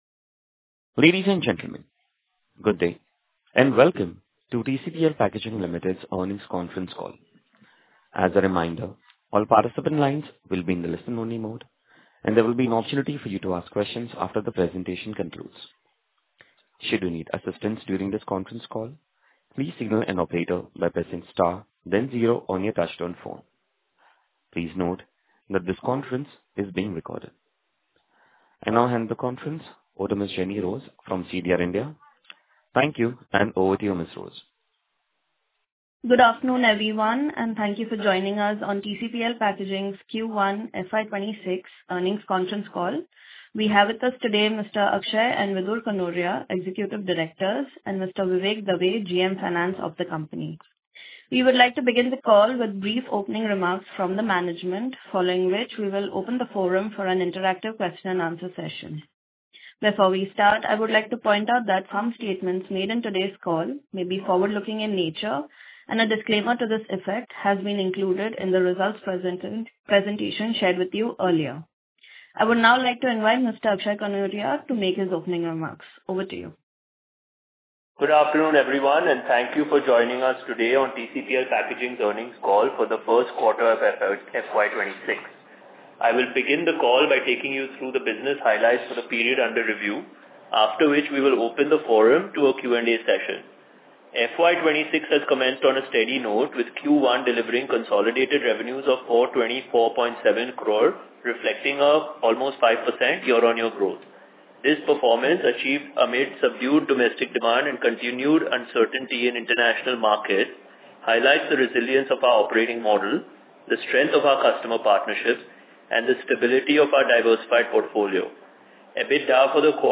TCPL-Packaging-Q1-FY26-Earnings-Call-Audio.mp3